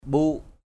/ɓu:ʔ/